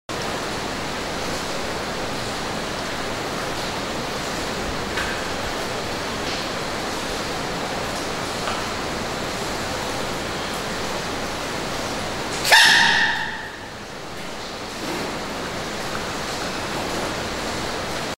🐼 "Sneezing Baby Panda" – sound effects free download
O vídeo original, gravado em um zoológico na Austrália, mostra uma mamãe panda tranquila com seu filhote, até que, do nada, o bebê solta um espirro estrondoso!